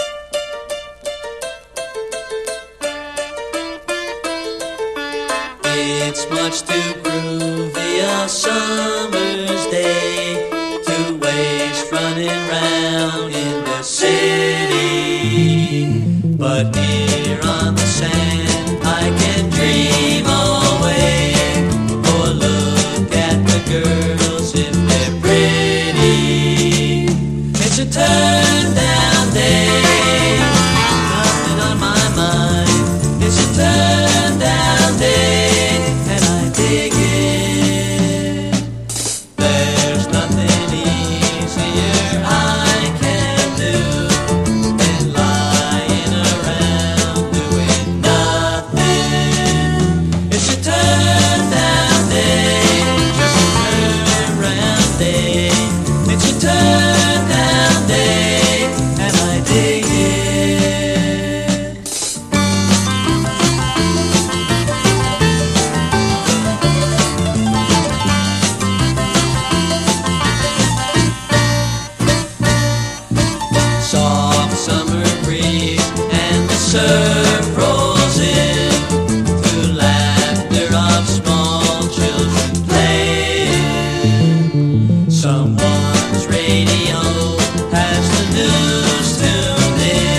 シタール使いのソフトロック・クラシック！